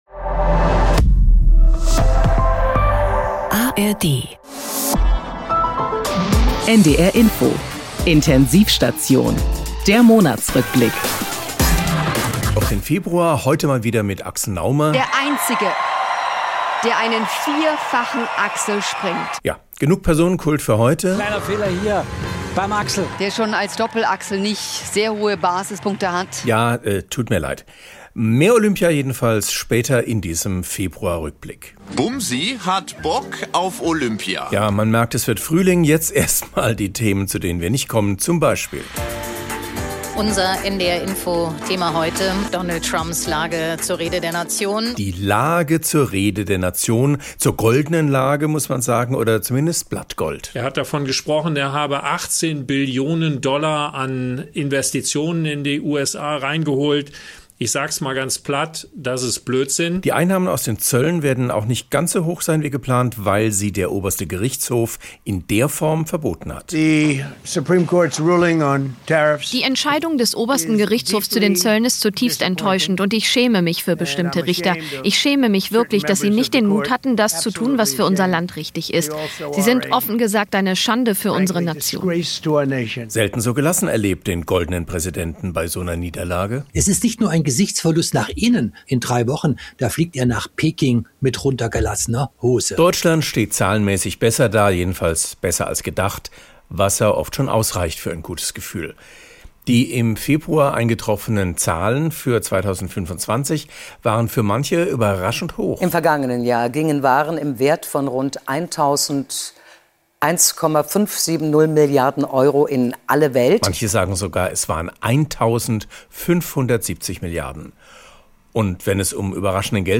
Heizen und Völkerrecht wieder Privatsache ~ Intensiv-Station - Satire von NDR Info Podcast